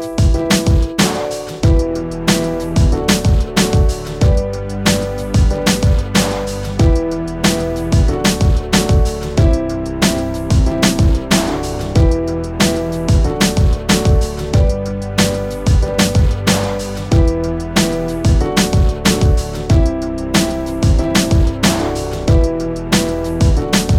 no Backing Vocals R'n'B / Hip Hop 4:52 Buy £1.50